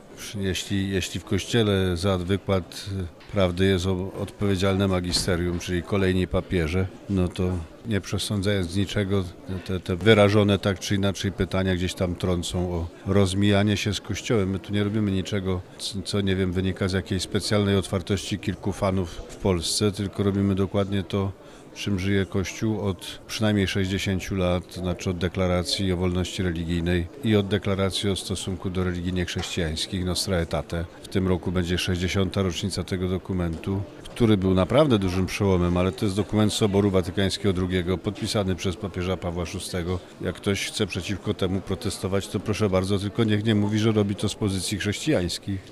W związku z odbywającym się 28. Dniem Judaizmu w Kościele Katolickim w Internecie pojawia się wiele negatywnych głosów, twierdzących, że jest to herezja lub pytających kiedy będzie dzień katolicyzmu w synagogach. Kard. Ryś odpowiada na te zarzuty.